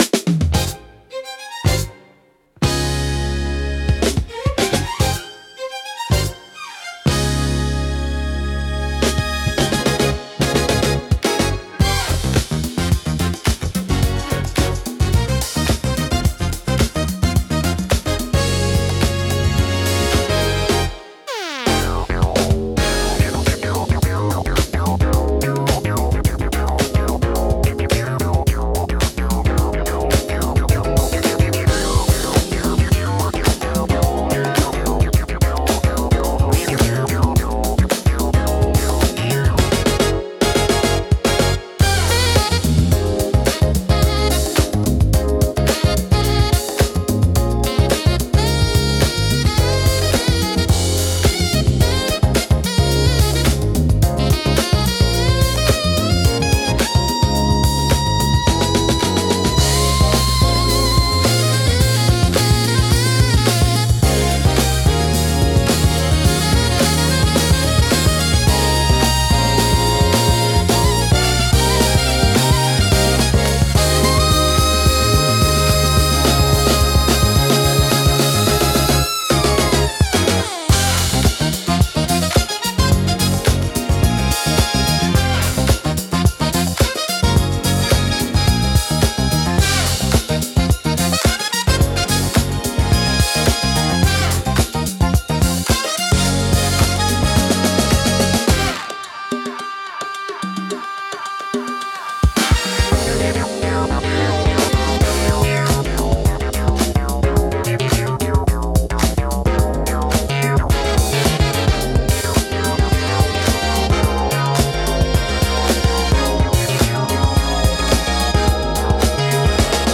エレガントでリズミカルな演奏が心地よく、ダンスフロアからカフェシーンまで幅広く親しまれています。
落ち着きつつも躍動感があり、聴く人の気分を盛り上げつつリラックスさせる効果があります。